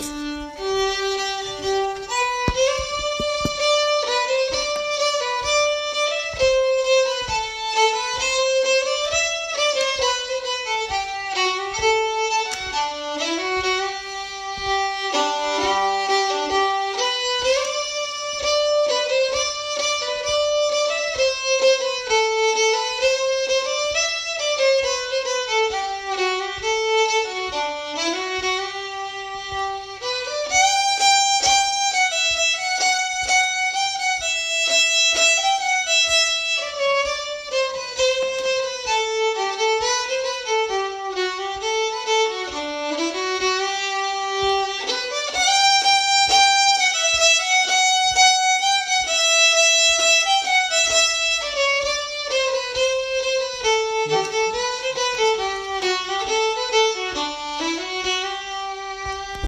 the processional tune is Gånglåt från Äppelbo
walking-tune-from-appelbo.m4a